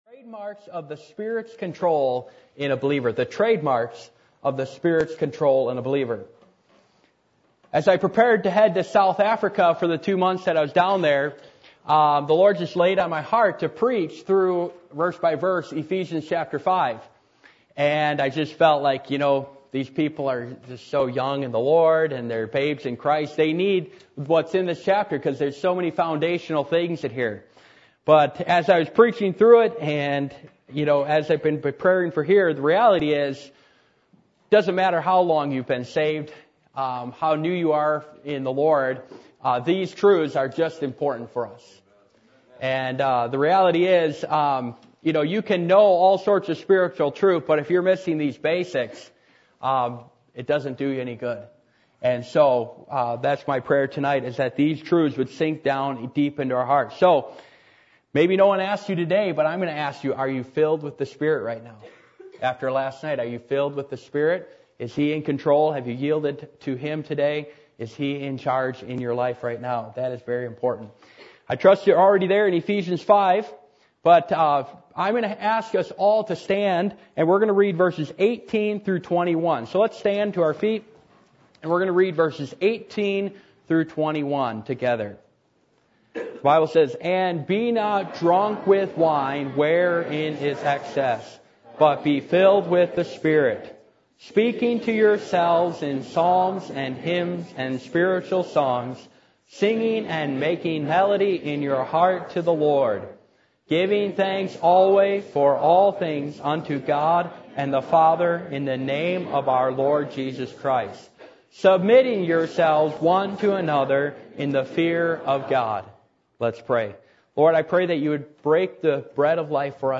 Ephesians 5:18-18:21 Service Type: Revival Meetings %todo_render% « Who is in Control?